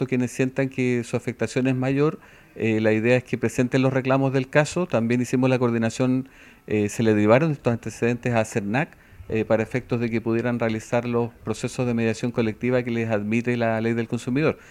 Por su parte, el superintendente de Electricidad y Combustible, Manuel Cartagena, explicó qué debían hacer aquellos que se vieran afectados por este hecho.
cuna-manuel-cartagena.mp3